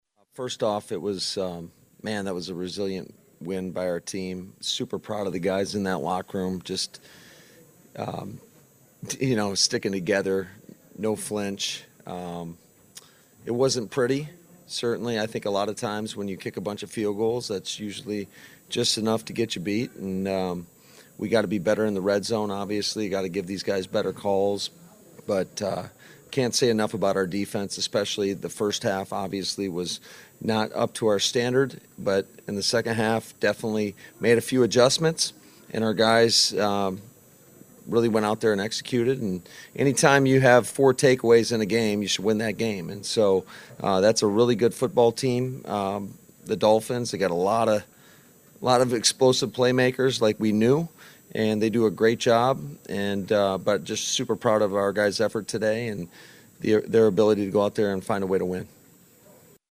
When it was over, Head Coach Matt LaFleur met the media.